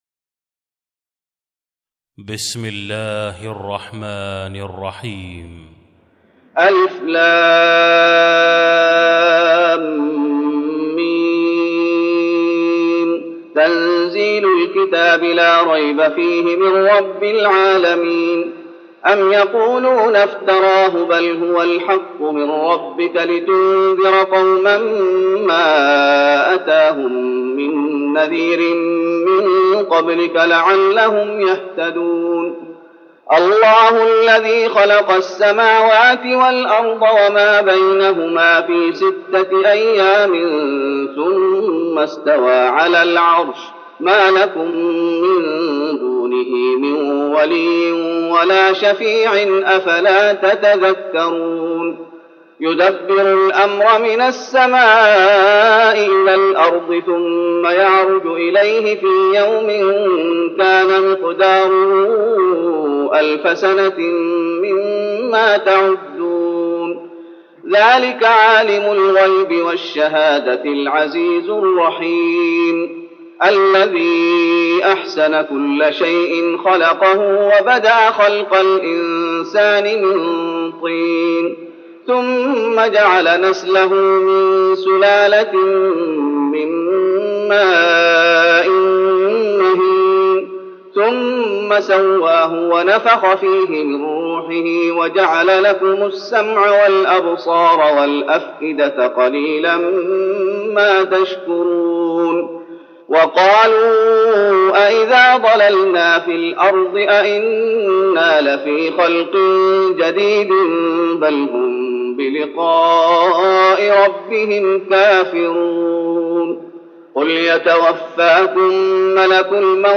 تراويح رمضان 1413هـ من سورة السجدة Taraweeh Ramadan 1413H from Surah As-Sajda > تراويح الشيخ محمد أيوب بالنبوي 1413 🕌 > التراويح - تلاوات الحرمين